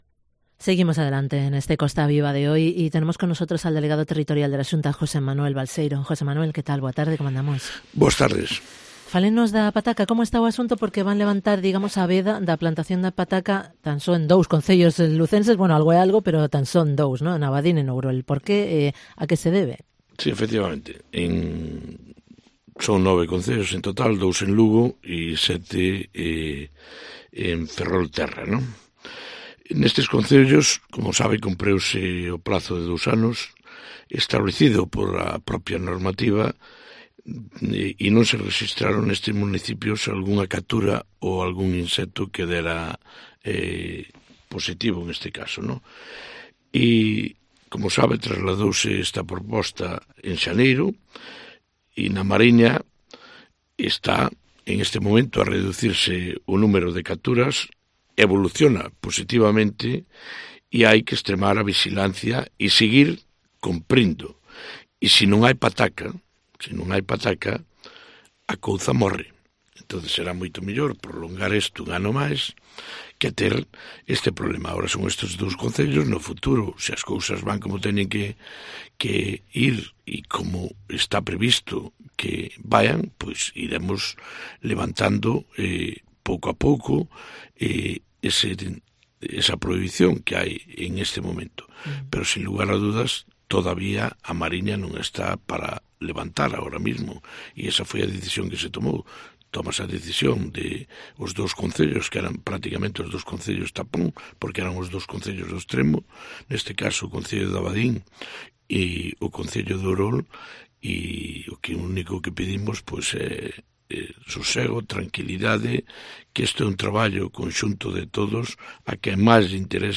ENTREVISTA con el delegado territorial de la Xunta, José Manuel Balseiro